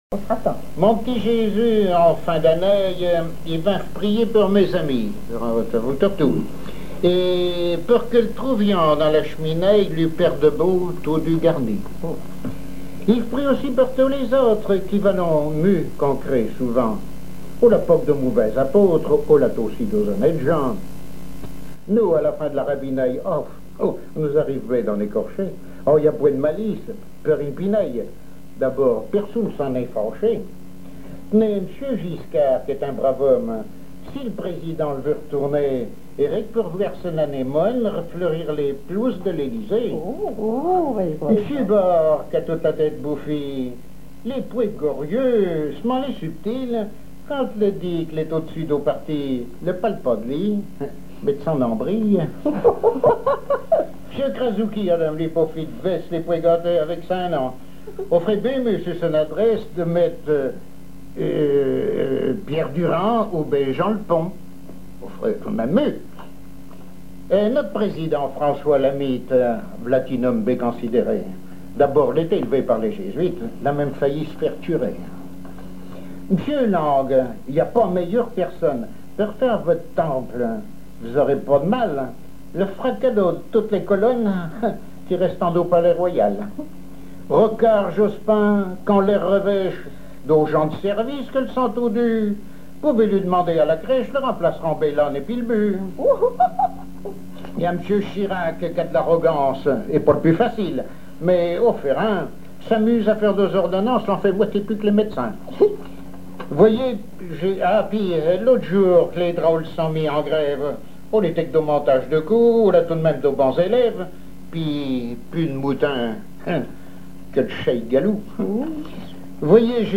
Langue Patois local
Genre conte
émission La fin de la Rabinaïe sur Alouette